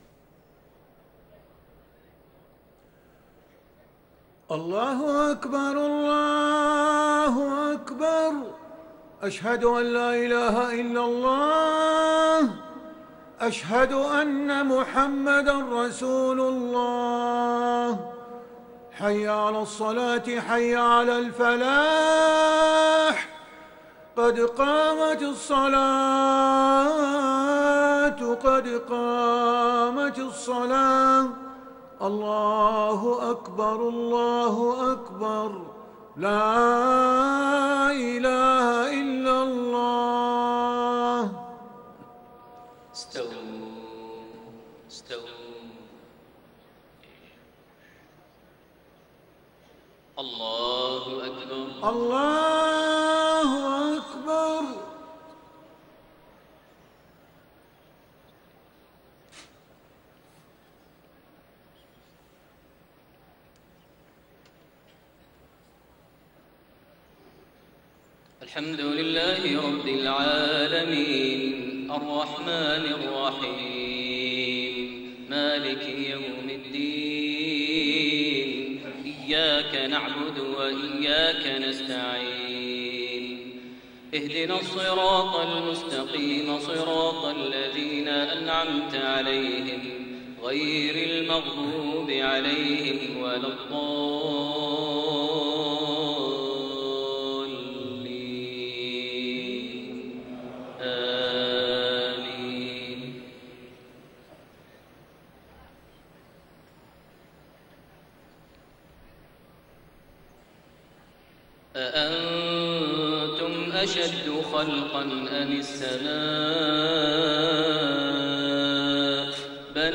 صلاة المغرب 22 ذو القعدة 1432هـ | خواتيم سورة النازعات 27-46 > 1432 هـ > الفروض - تلاوات ماهر المعيقلي